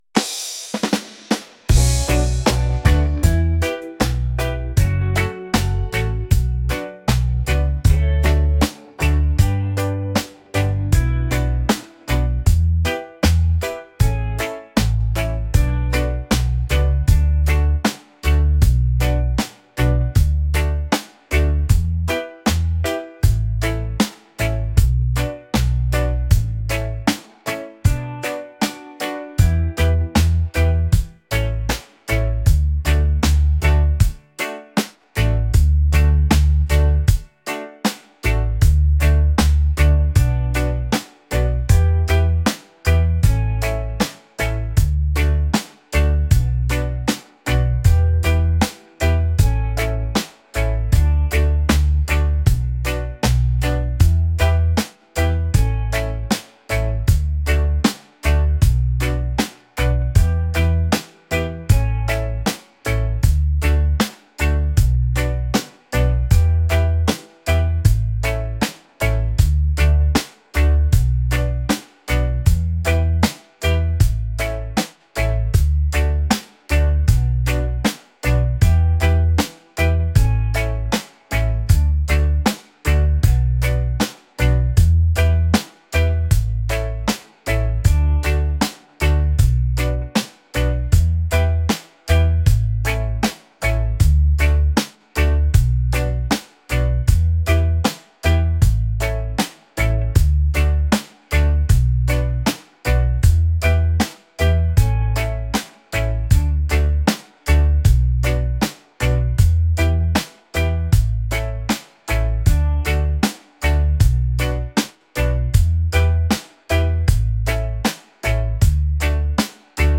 laid-back | reggae | soulful